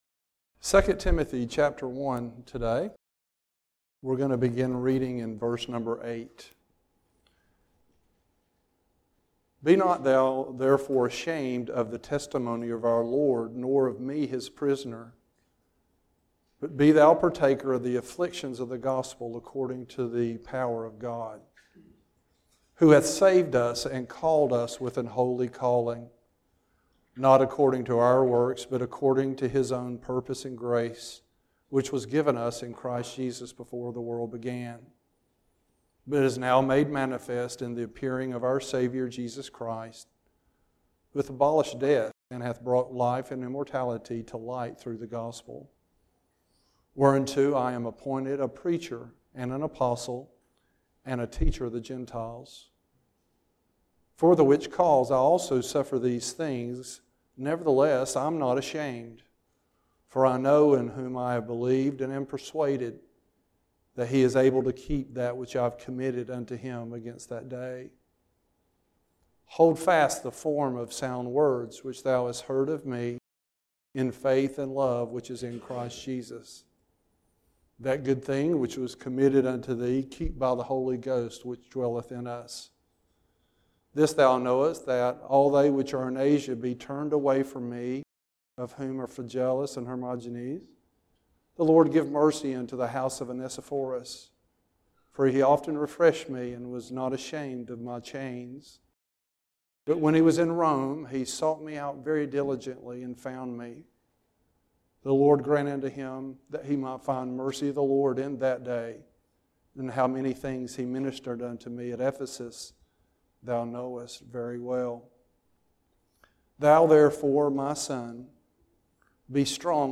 Timothy Passage: 2 Timothy 1:8-2:7 Service Type: Sunday AM « Children